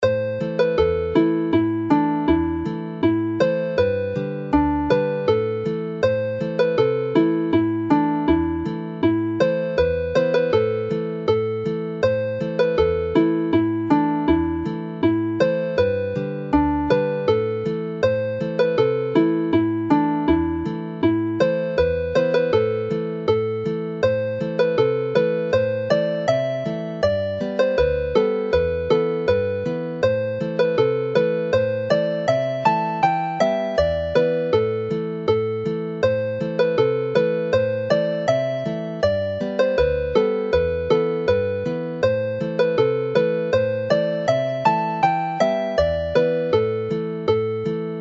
in A minor
Play the melody slowly